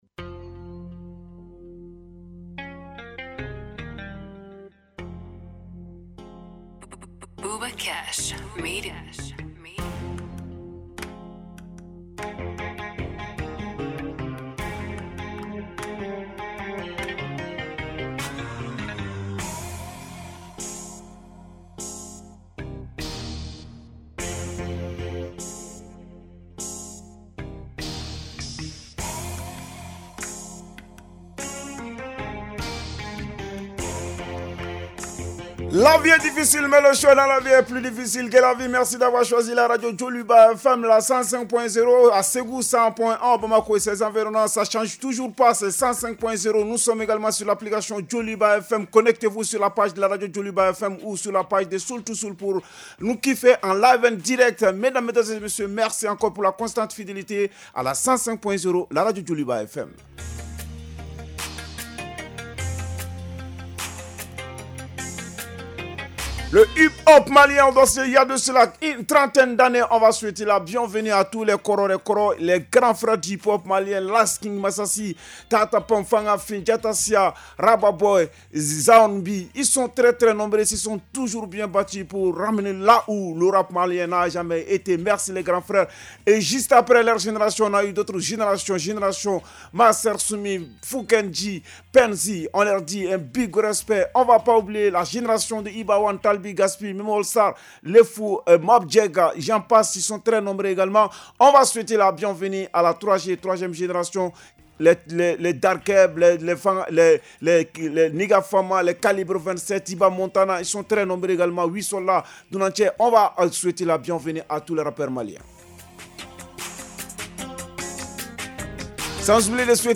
Un programme 100 % dédié à la scène rap et hip-hop du Mali avec des interviews exclusives, des freestyles et toute l’actualité croustillante de vos rappeurs préférés.